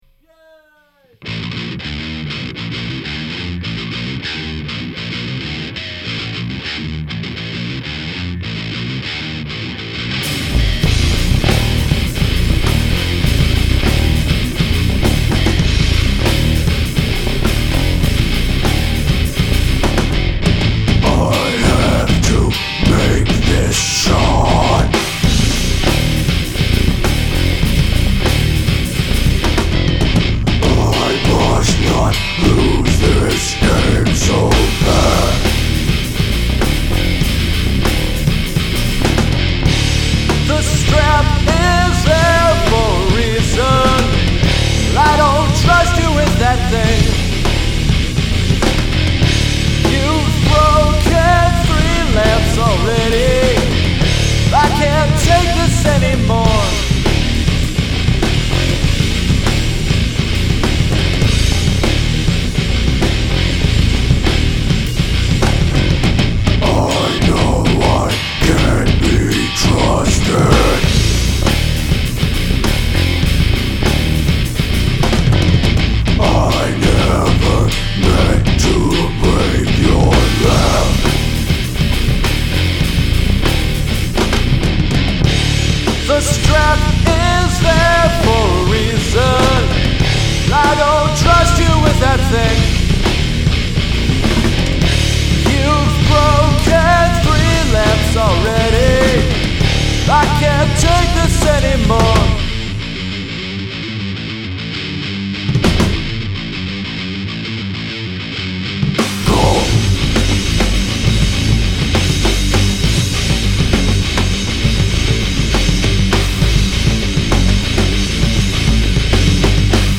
MORE METAL!
All instruments and mixing by me.